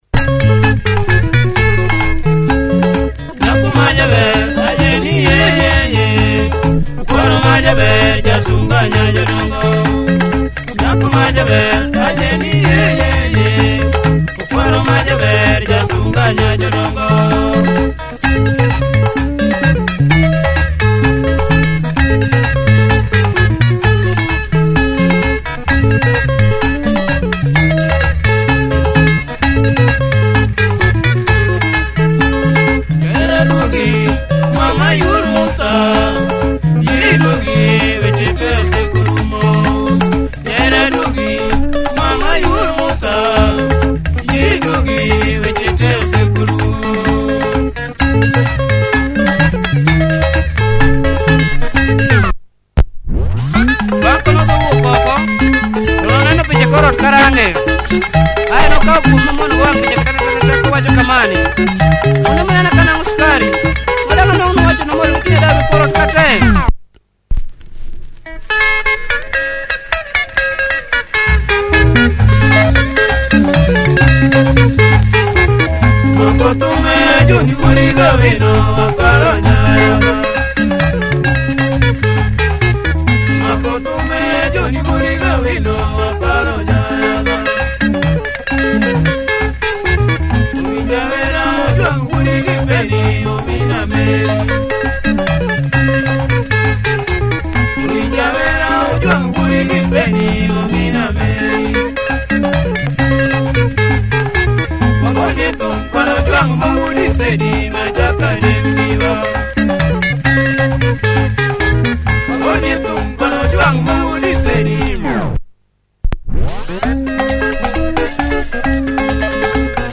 Nice Luo benga.